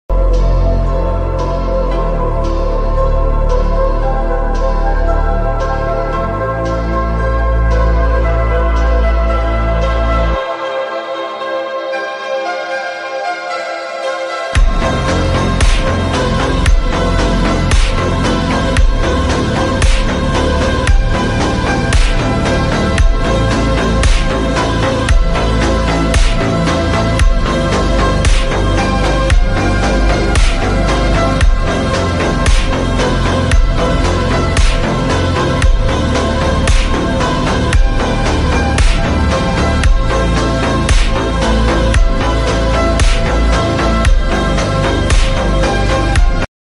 POV Riding tengah malam melewati sound effects free download
POV Riding tengah malam melewati persawahan